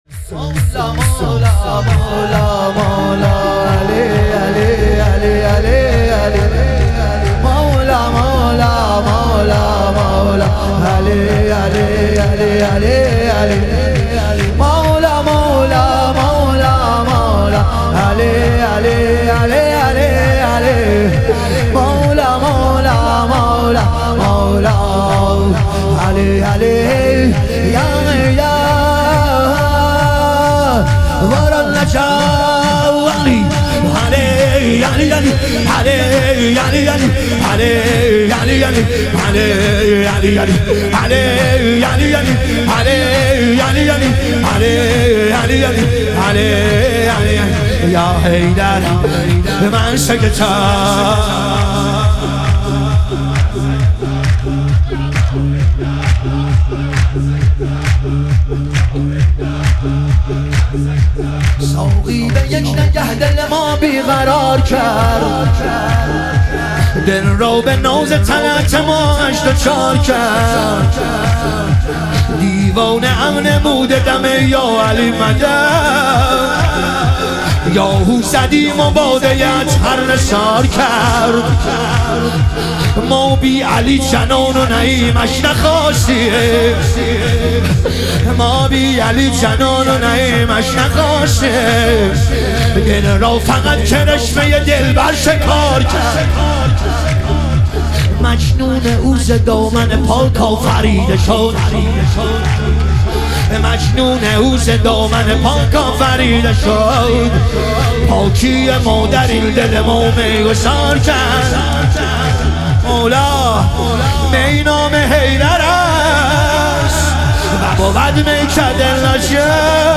ظهور وجود مقدس حضرت قاسم علیه السلام - شور